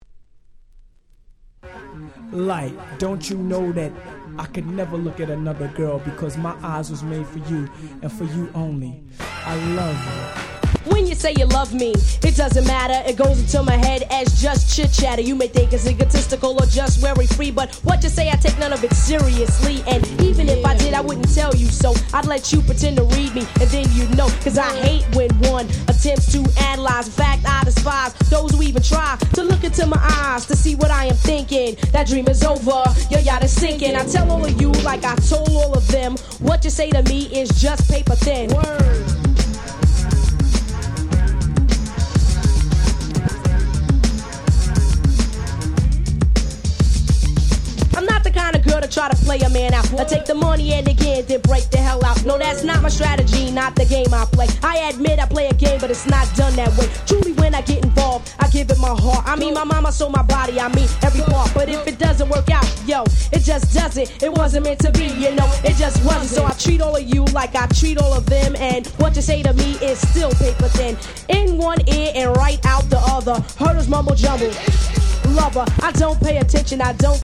88' Hip Hop Super Classics !!